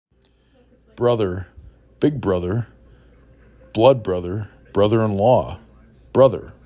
bro ther
b r uh D er